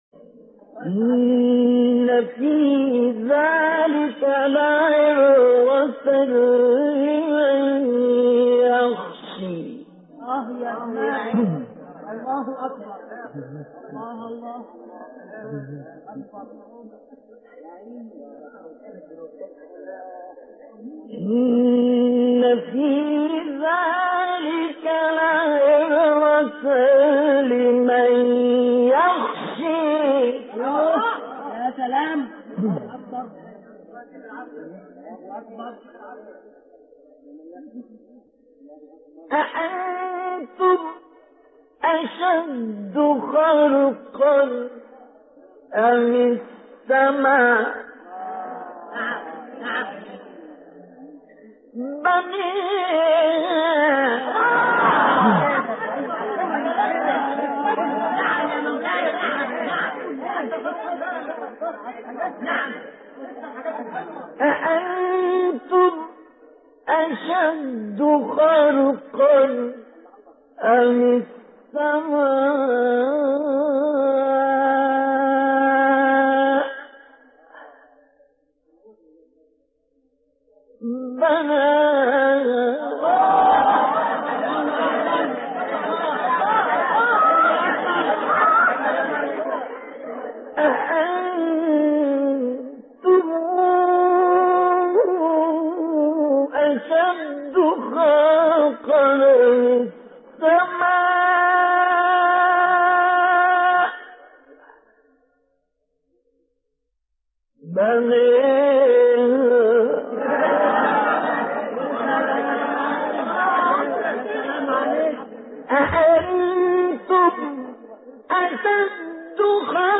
حیات اعلی :: دریافت 22- نازعات = بوستان تلاوت 98